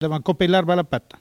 Maraîchin
Patois